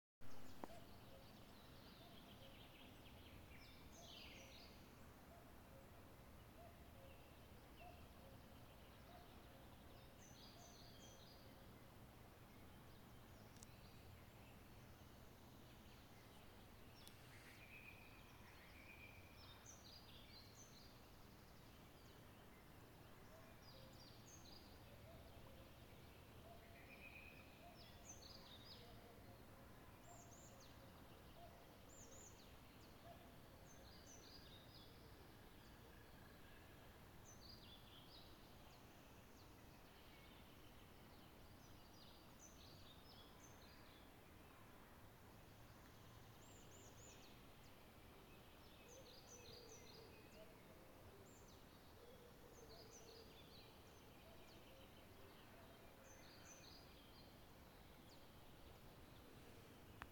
Putns (nenoteikts), Aves sp.
СтатусСлышен голос, крики